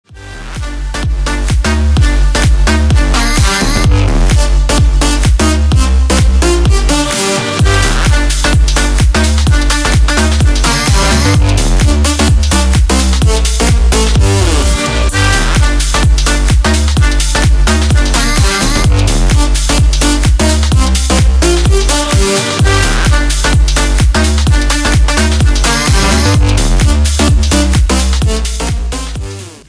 • Качество: 320, Stereo
ритмичные
громкие
заводные
dance
без слов
club
качающие
быстрые
Bass
electro house
electro
бас
в ритме денса